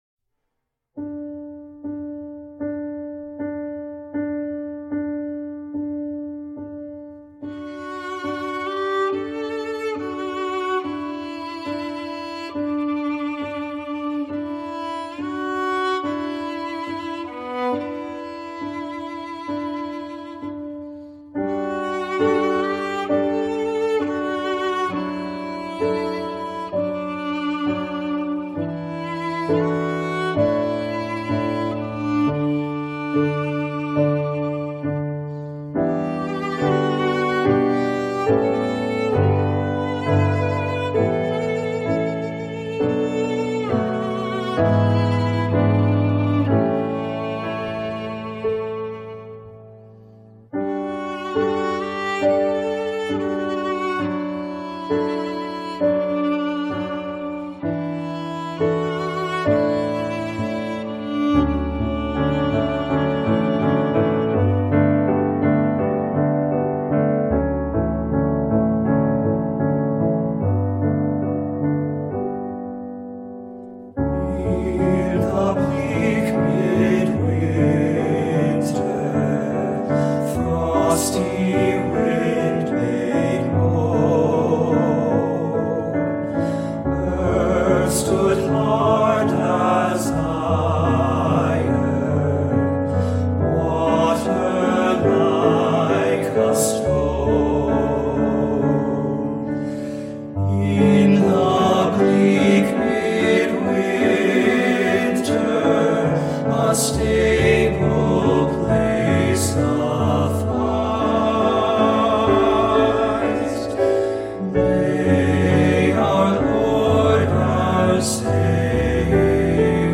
for Violin, SA Flute, and Three Part Men
Three Part Men, SA Flute, Violin